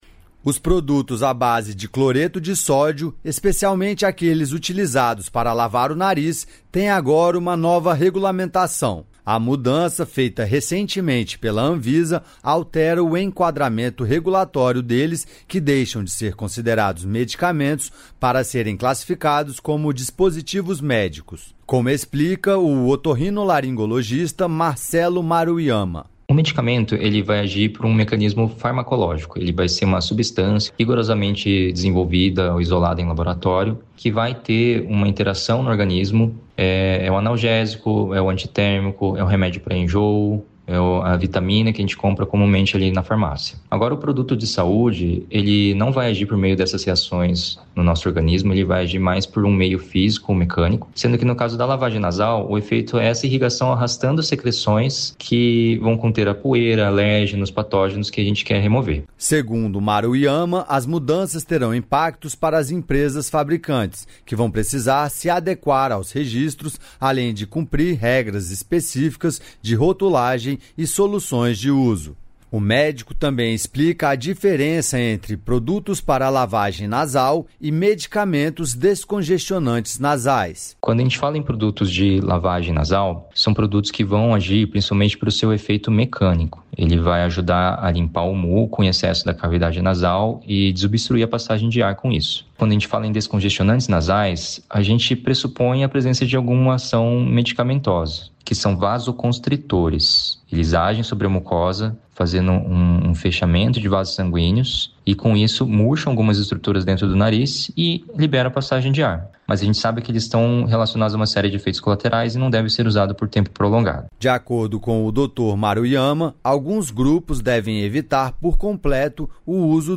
A ideia do encontro é trocar experiências sobre o trabalho realizado na Casa da Mulher e também atualizar diretrizes e protocolos de atendimento, como explica a ministra das Mulheres, Cida Gonçalves. A ministra dos Povos Indígenas, Sônia Guajajara, destacou a importância do acolhimento diferenciado.